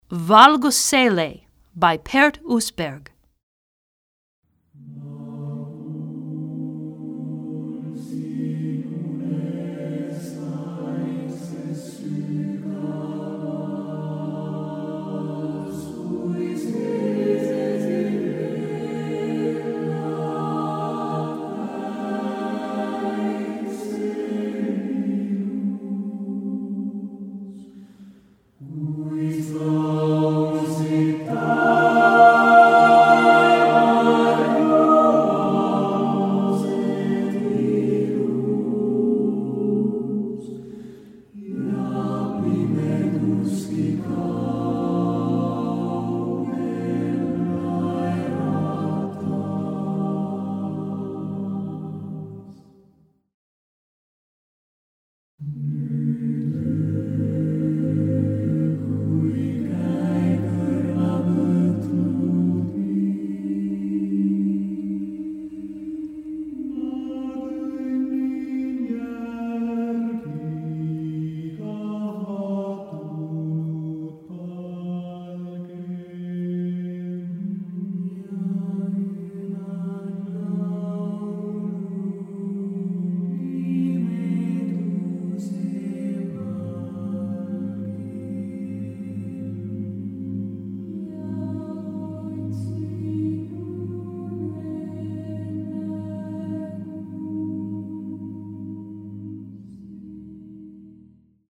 Choeur TTBB